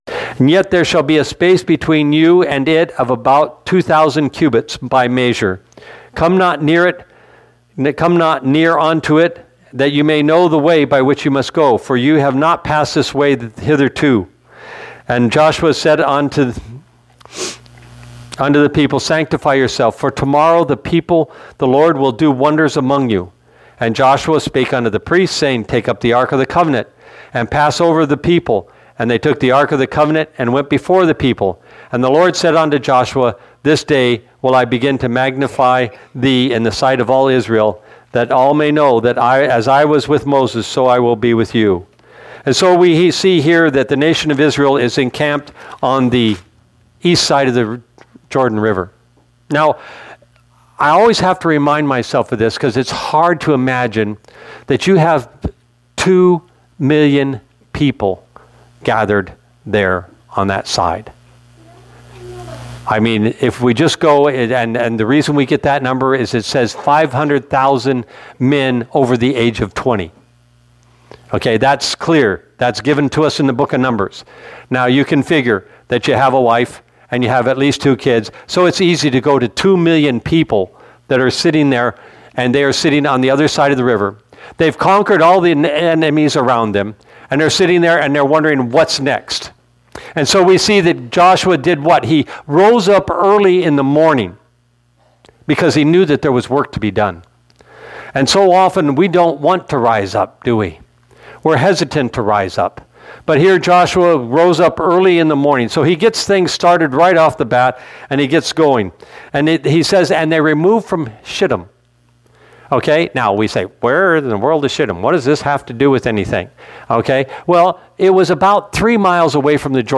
Late start of recording, my apologies
Sermons